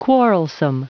Prononciation du mot quarrelsome en anglais (fichier audio)
Prononciation du mot : quarrelsome